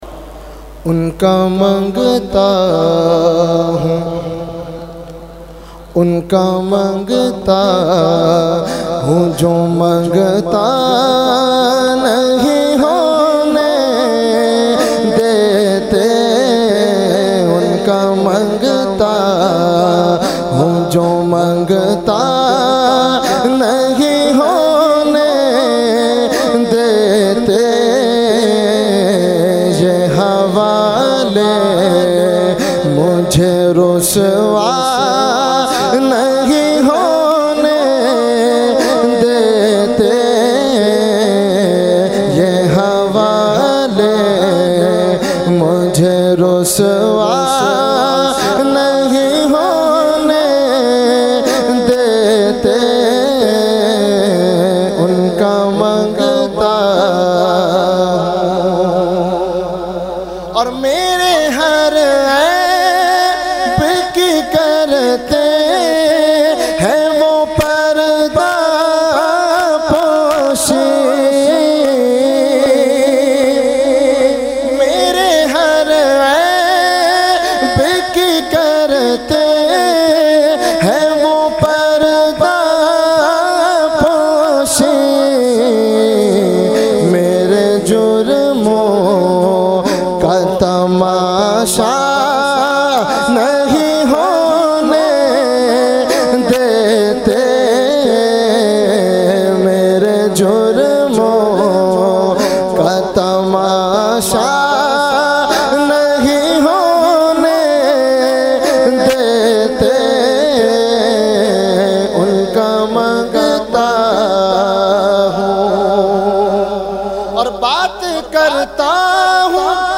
Category : Naat | Language : UrduEvent : Urs Ashraful Mashaikh 2020